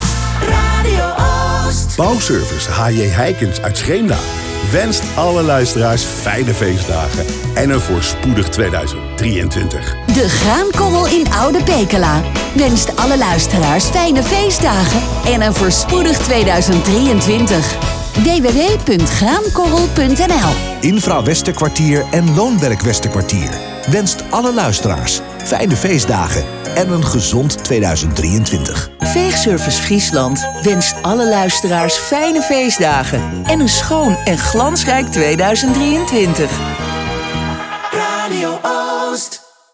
Kerstcommercials
Demo-Kerstcommercials-Radio-Oost.wav